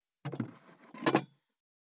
209,机に物を置く,テーブル等に物を置く,食器,グラス,コップ,工具,小物,雑貨,コトン,トン,
コップ効果音物を置く